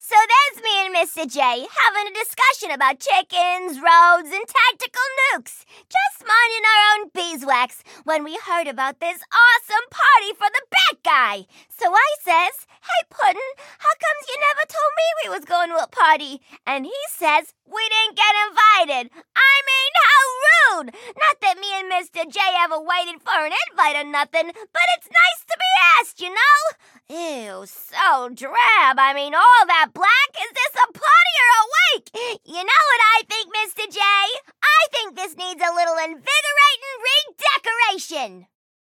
Song: dialogue - batman75_intro_dx_eng